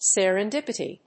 音節ser・en・dip・i・ty 発音記号・読み方
/sèrəndípəṭi(米国英語), ˌsɛ.rɛn.ˈdɪp.ə.ti(英国英語)/